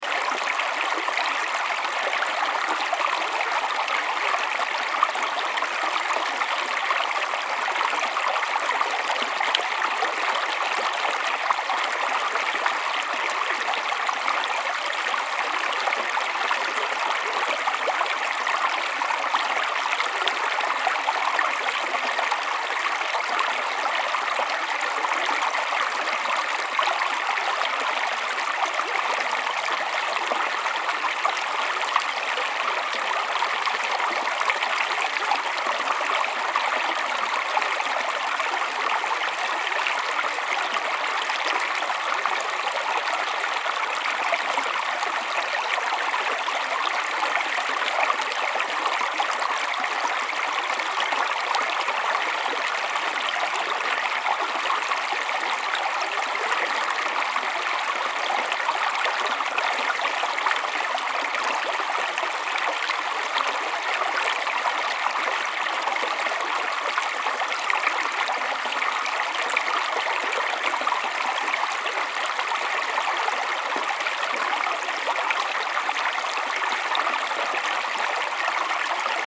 Mountain-stream-sound-effect.mp3